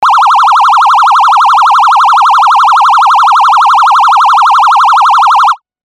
クイズ回答伏せ
ピヨヨヨ